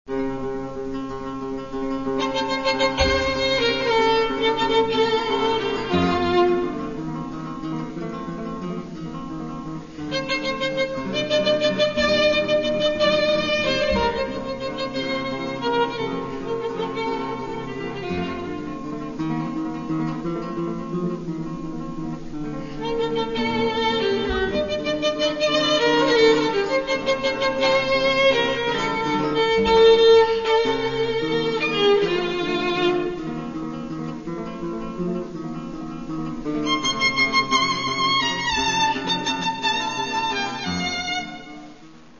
Тому що це – запис живого концерту.